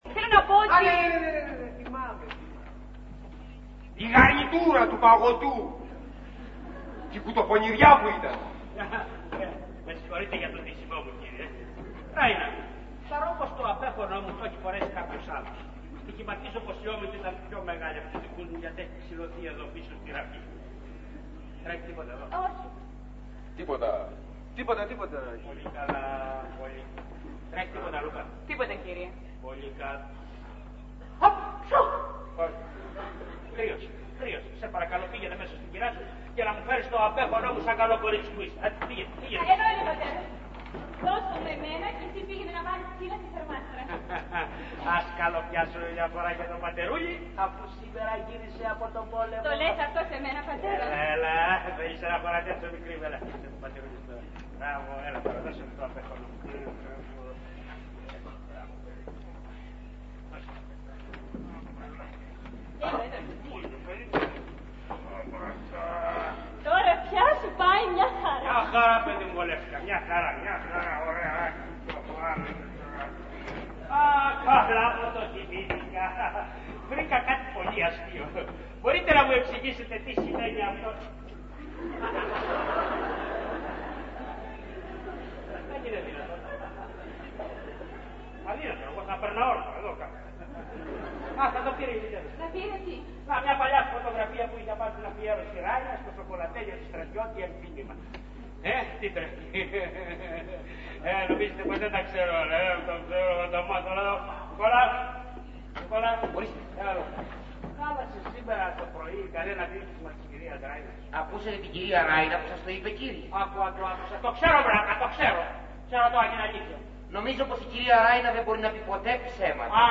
Ηχογράφηση Παράστασης
Αποσπάσματα από την παράσταση
sound 2'30'', Λούκα-Σέργιος-Πέτκωφ-Ράινα-Νικόλας-Μπλούντσλι